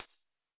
Index of /musicradar/ultimate-hihat-samples/Hits/ElectroHat A
UHH_ElectroHatA_Hit-08.wav